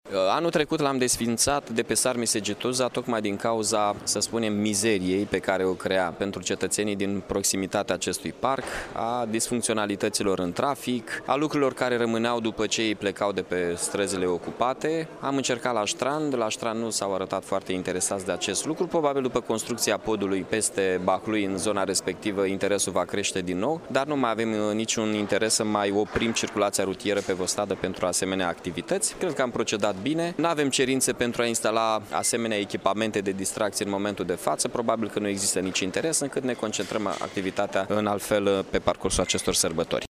Primarul Iaşului Mihai Chirica a precizat că odată cu mutarea locaţiei Parcului de distracţii de pe strada Sarmisegetuza în incinta Ştrandului a scăzut numărul societăţilor comerciale care doreau să desfacă produse, iar în acest an nu a mai fost nici o solicitare: